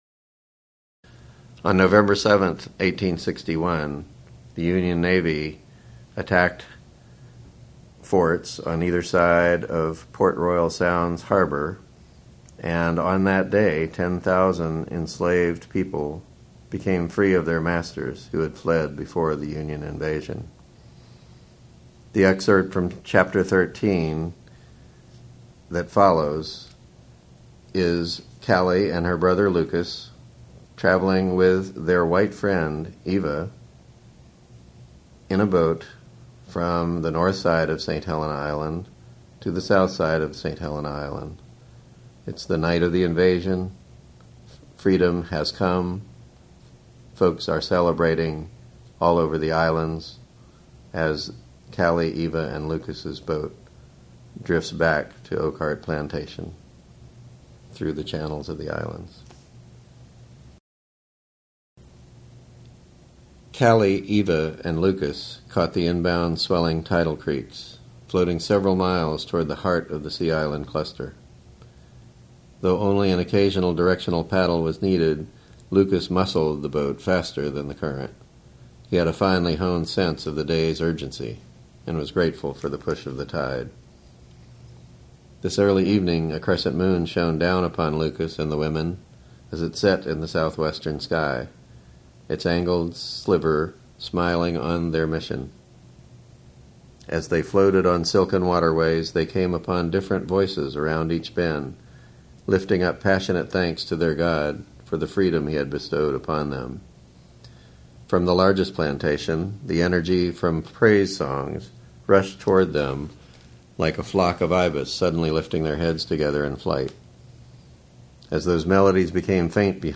read by the author